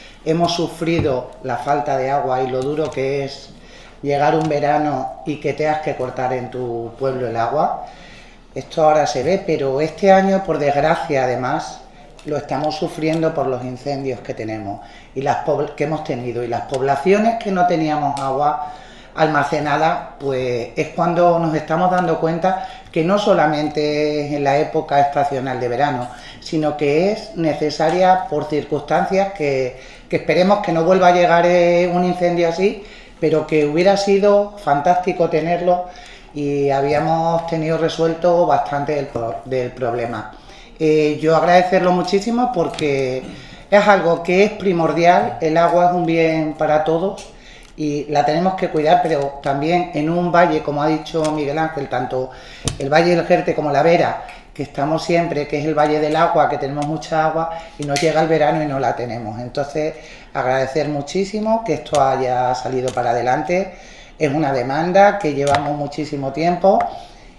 CORTES DE VOZ
Marisa Yusta (67 segundos)